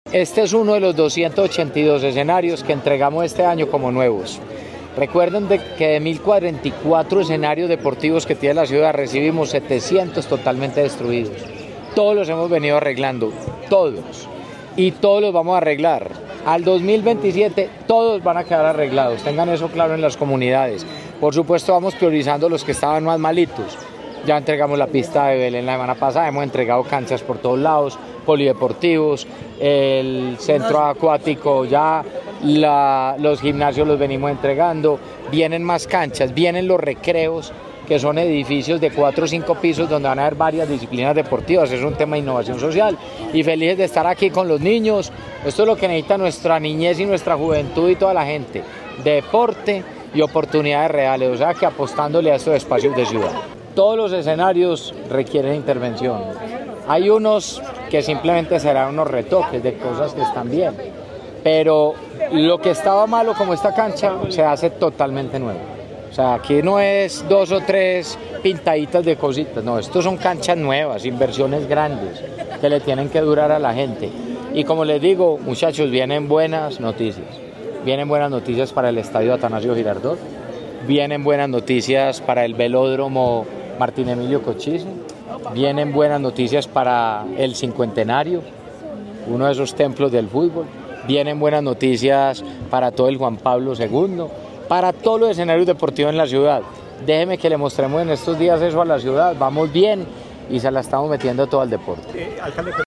Declaraciones-alcalde-de-Medellin-Federico-Gutierrez-6.mp3